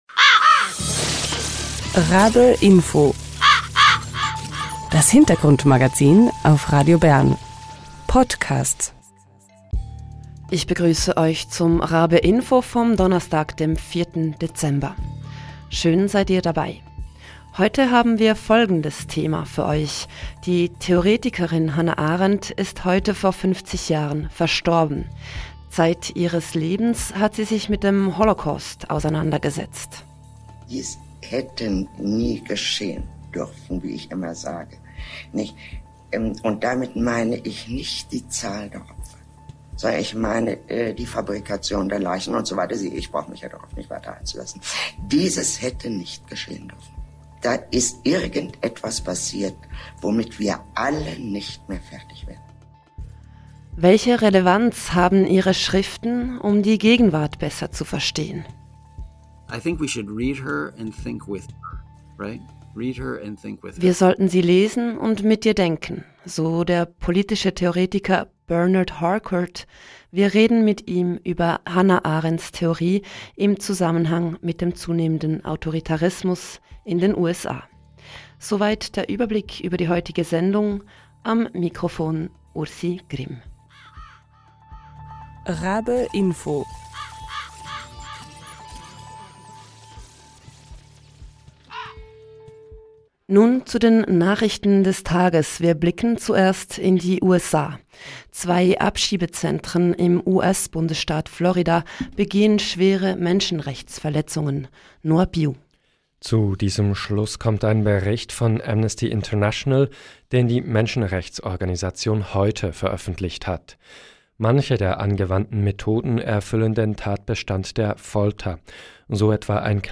Lässt sich die Verschiebung hin zum Autoritarismus in den USA besser verstehen, mit den Schriften von Hannah Arendt? Darüber spricht Bernard Harcourt im Talk.
Der Talk ist eine Wiederholung.